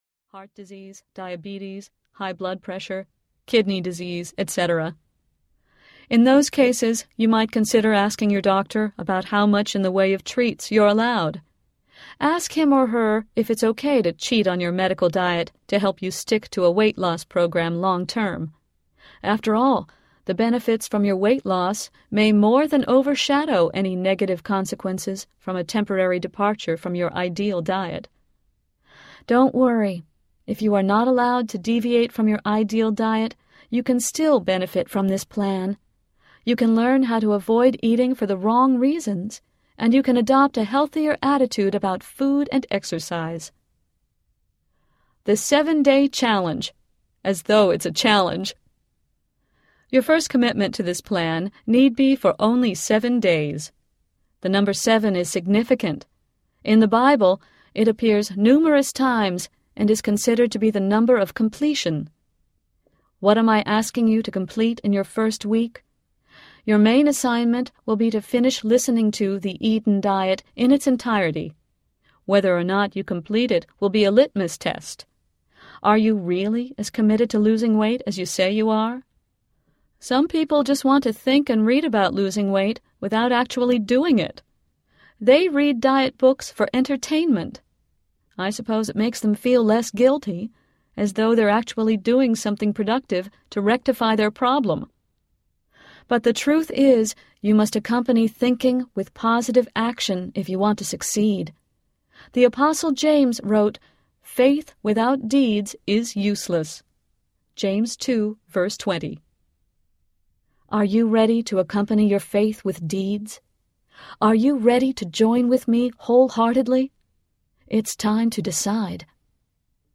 The Eden Diet Audiobook
Narrator